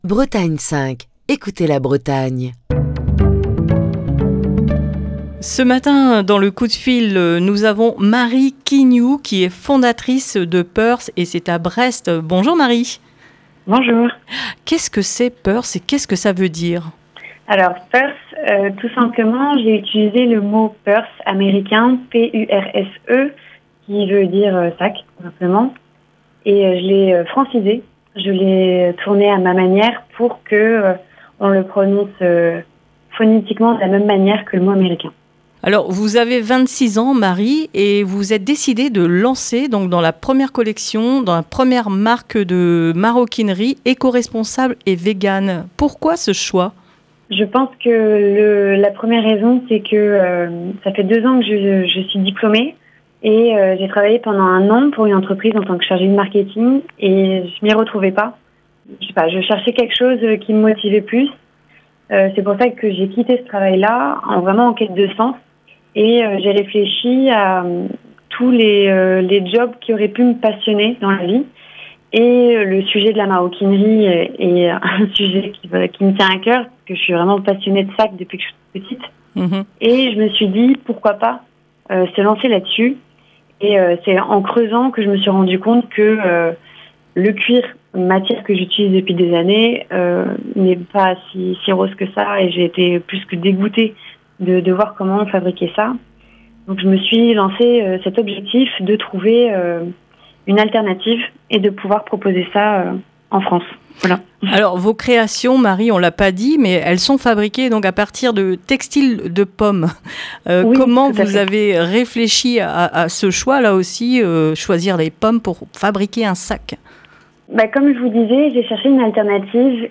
Dans ce coup de fil du matin du lundi